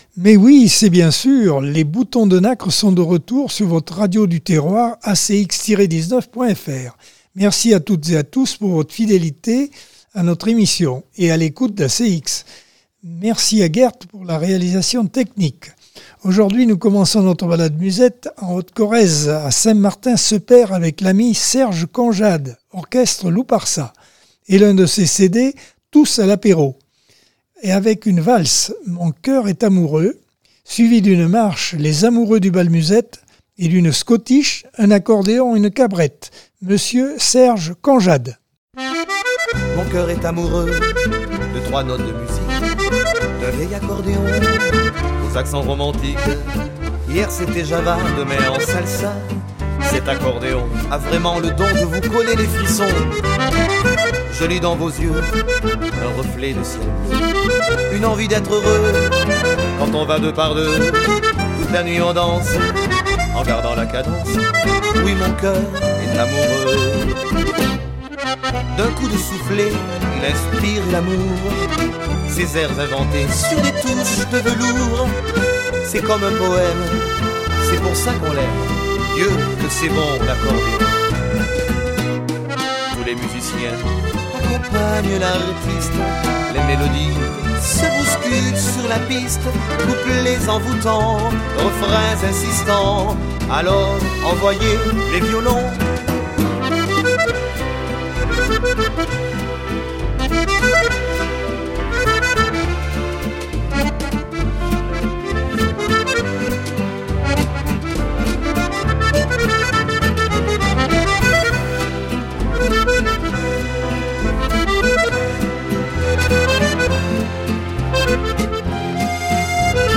Mardi par Accordeon 2023 sem 26 bloc 1.